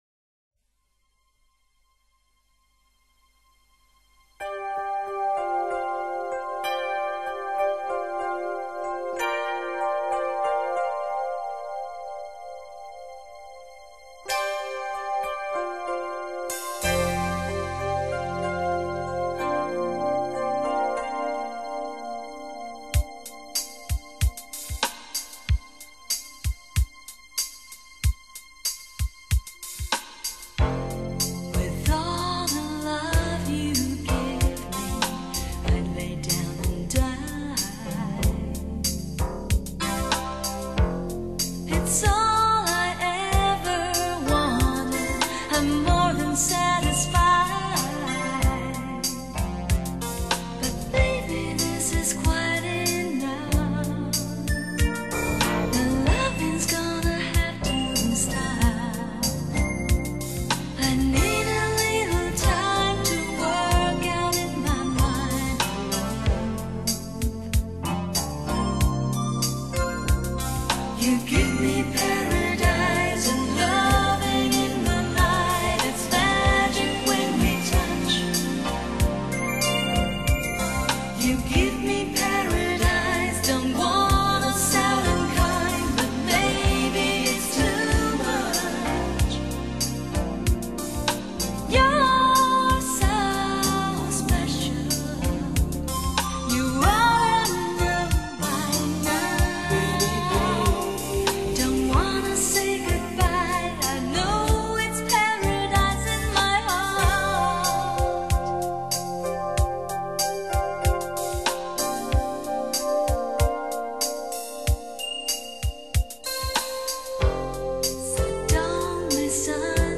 营绕浓浓东方味